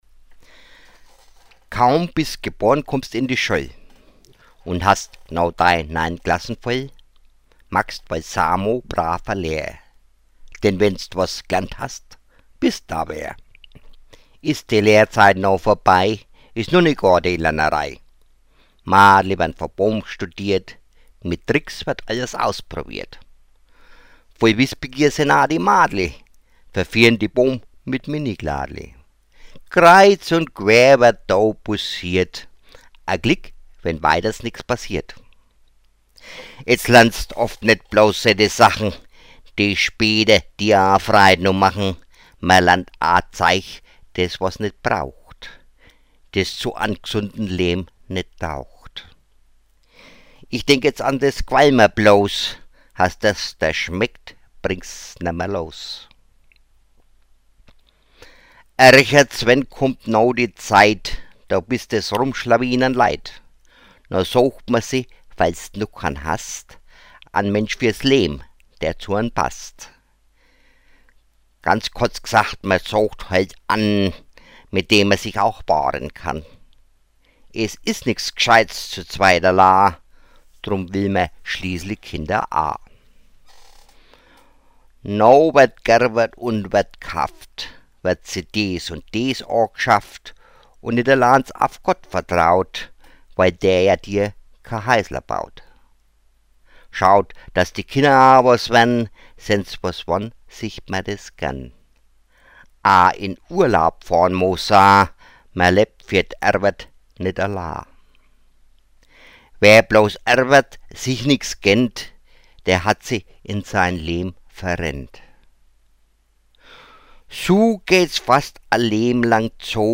Meine Mundart-Gedichte More Use tab to navigate through the menu items.